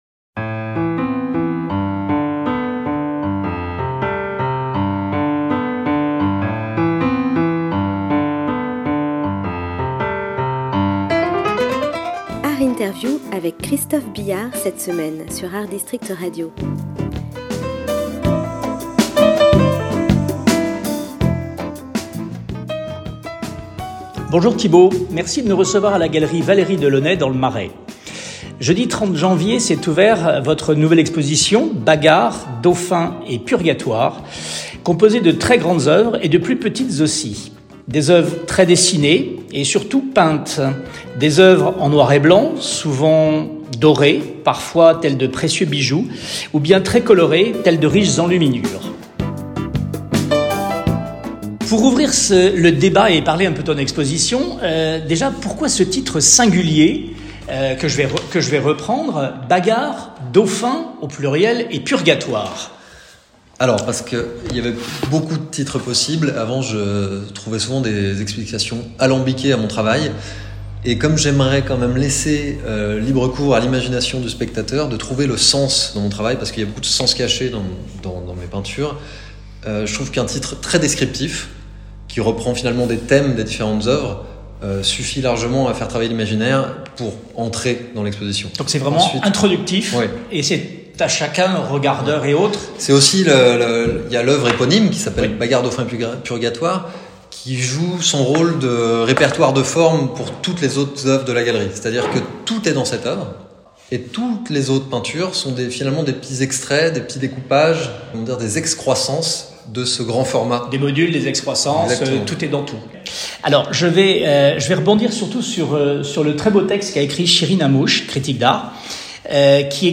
ART INTERVIEW lundi et jeudi à 14h.